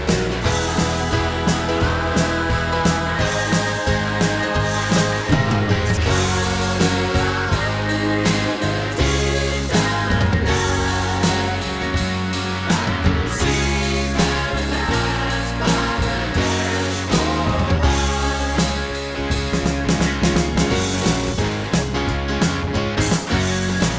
One Semitone Down Rock 7:34 Buy £1.50